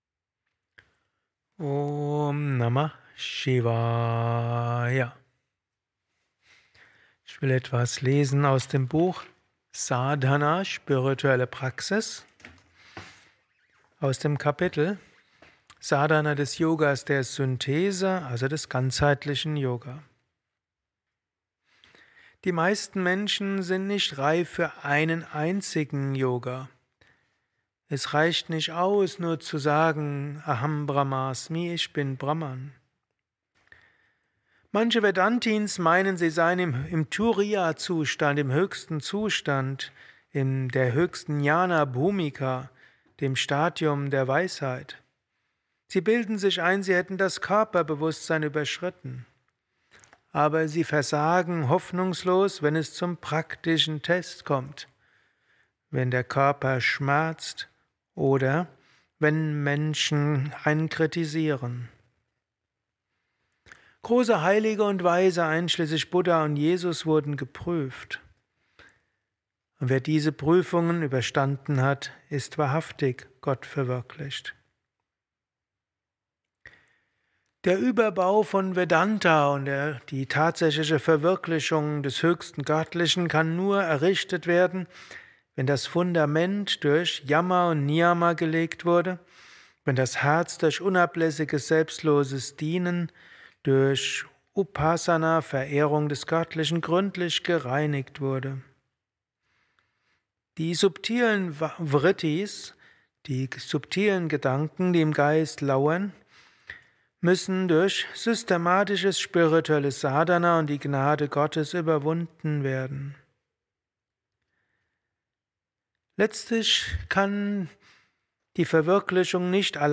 Satsangs gehalten nach einer Meditation im Yoga Vidya Ashram Bad